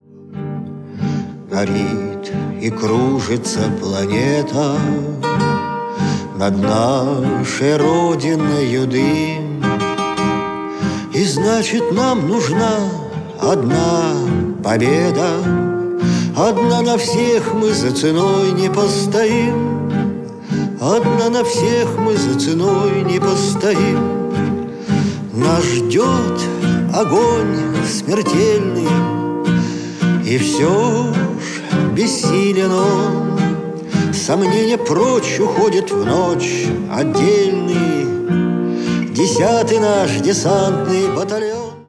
ретро